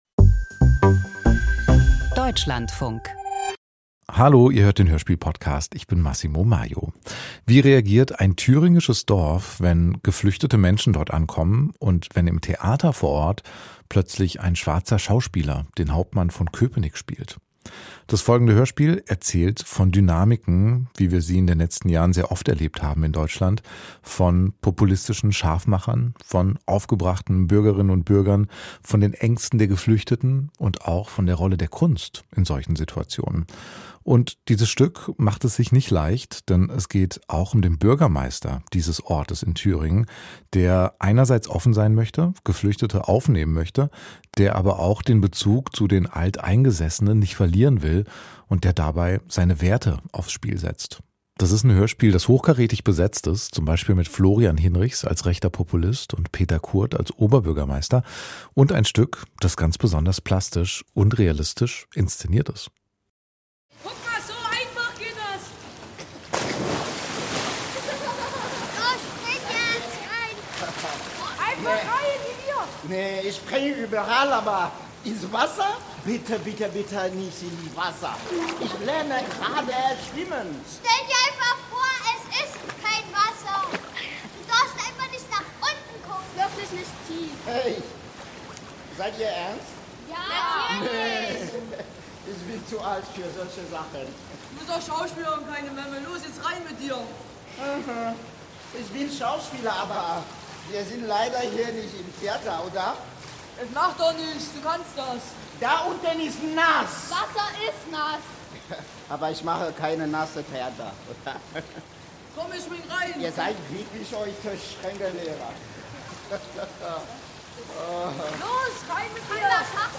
• Drama • Leerstadt schrumpft. Die Landrätin begegnet dem demografischen Wandel mit der Aufnahme von Geflüchteten.